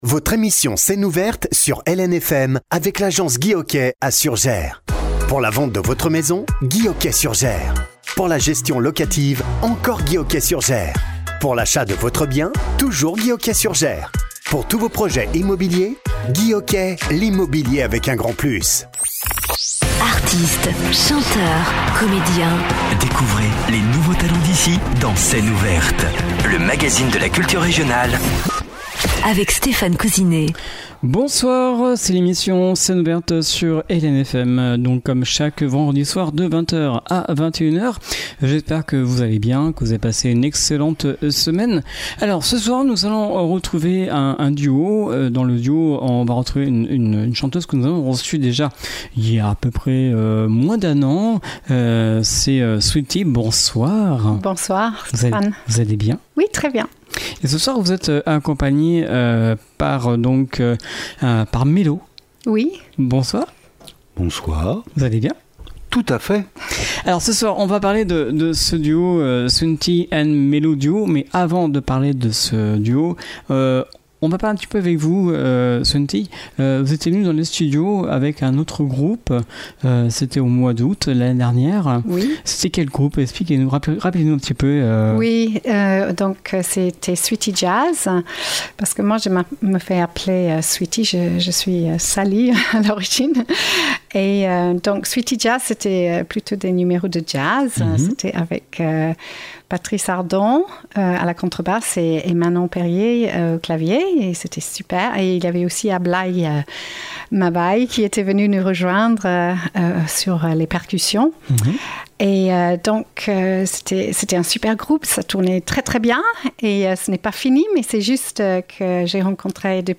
une chanteuse d’origine anglaise
un guitariste et chanteur talentueux. Leur rencontre a été le fruit d’une passion commune pour le jazz, le folk et la musique brésilienne, des genres qui ont su les unir et les inspirer.